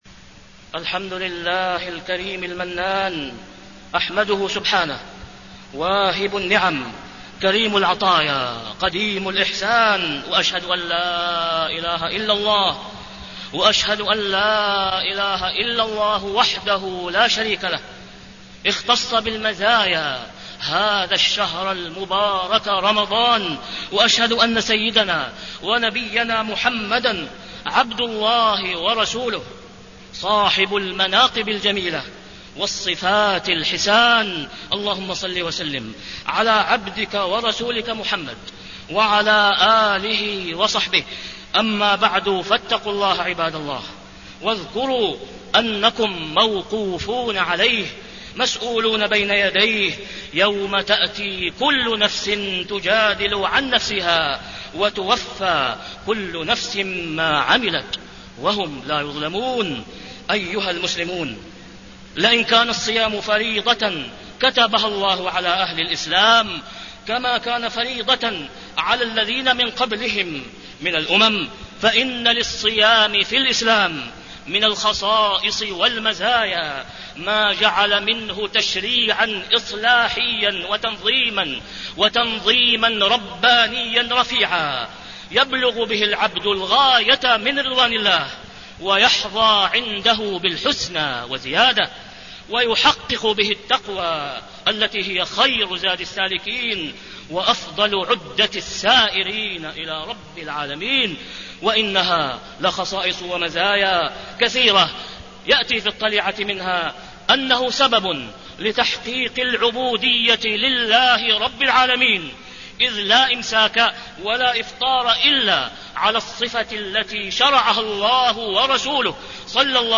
تاريخ النشر ٨ رمضان ١٤٣٣ هـ المكان: المسجد الحرام الشيخ: فضيلة الشيخ د. أسامة بن عبدالله خياط فضيلة الشيخ د. أسامة بن عبدالله خياط مزايا الصيام في الإسلام